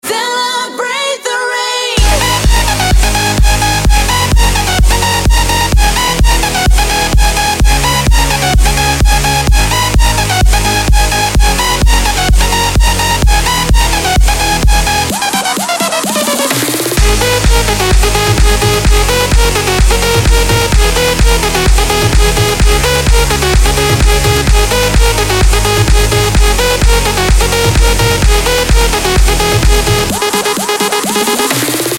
Новая взрывная Электронщина